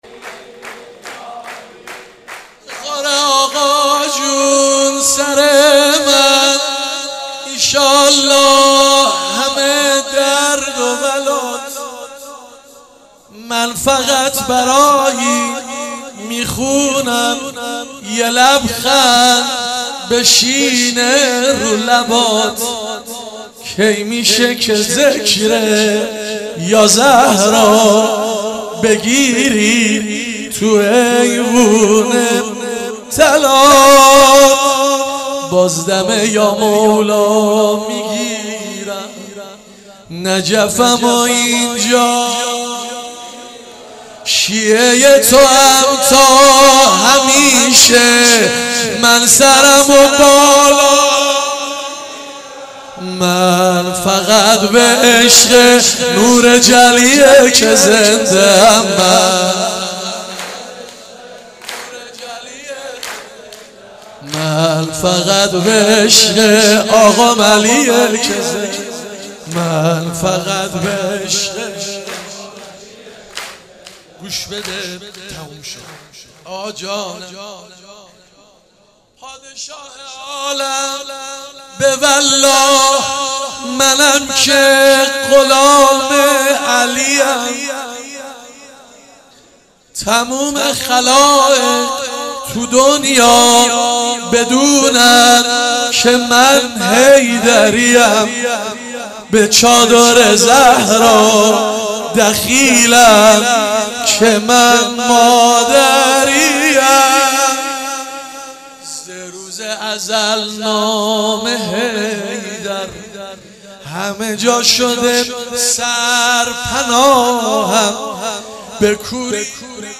مراسم هفتگی هیئت علمدار
چهاراه شیرودی حسینیه حضرت زینب(س)